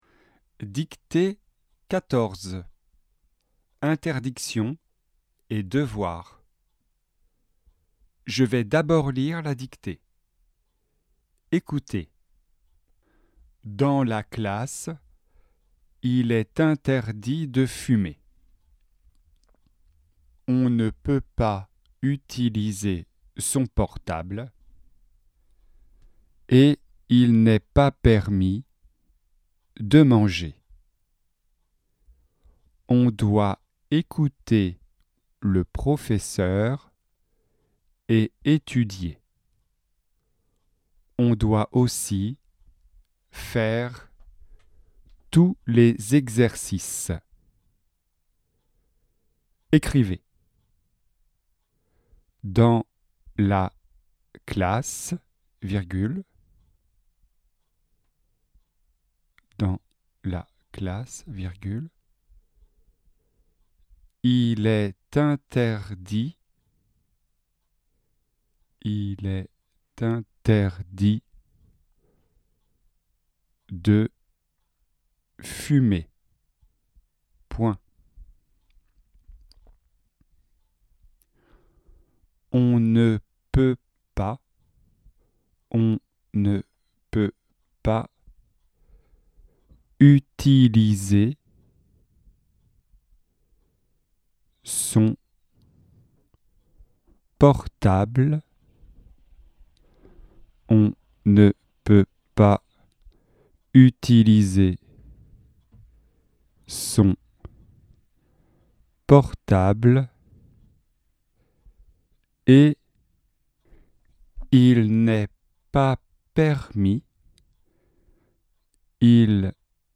Dictée 14 : interdictions & devoirs